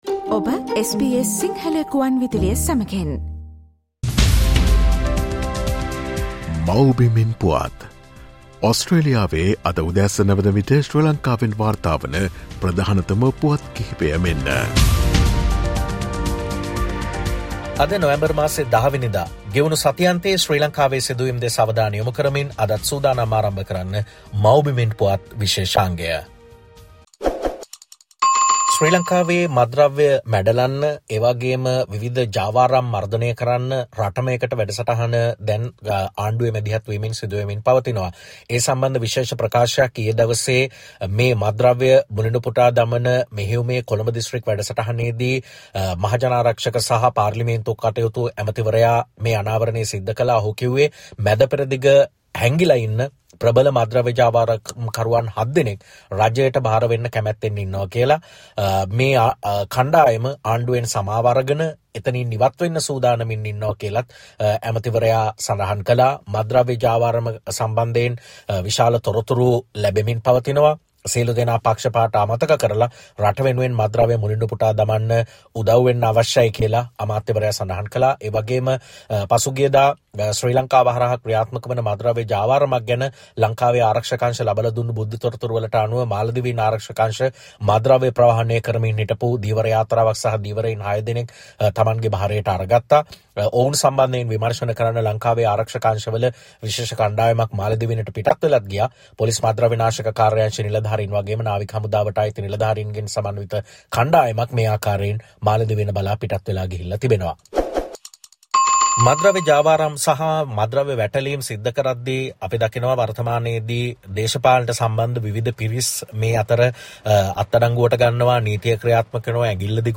ගෙවුණු සතිඅන්තයේ ශ්‍රී ලංකාවෙන් වාර්තා වූ උණුසුම් හා වැදගත් පුවත් සම්පිණ්ඩනය.